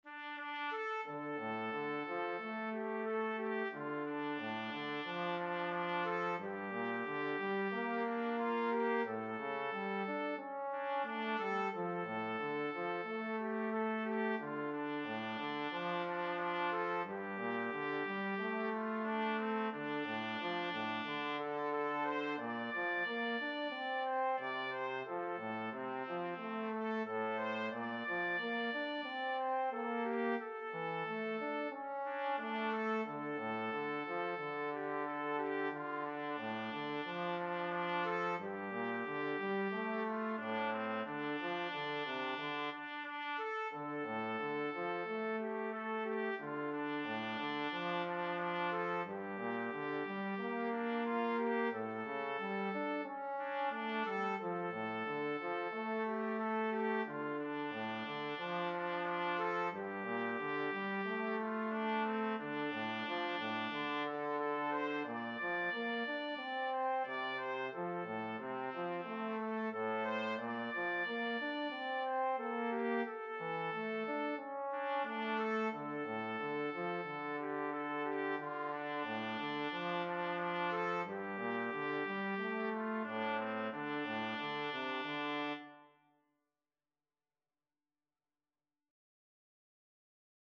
4/4 (View more 4/4 Music)
Andante = c. 90